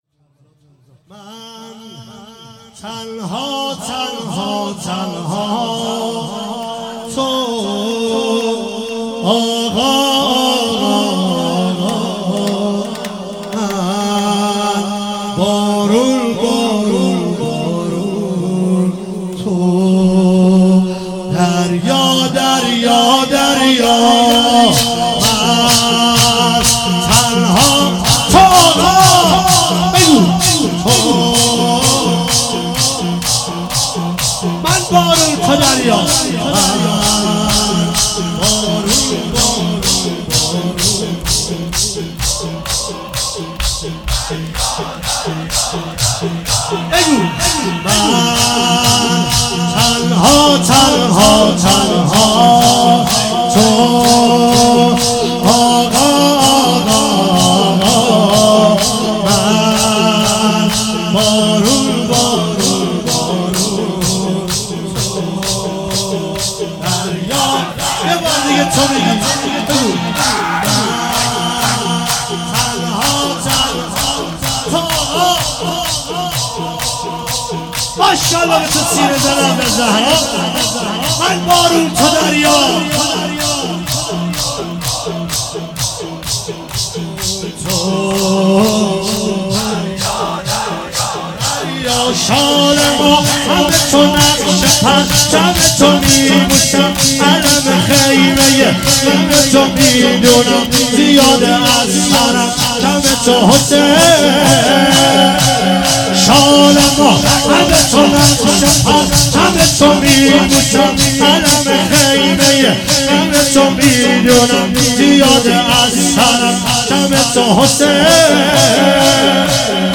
فاطمیه 95
شور مداحی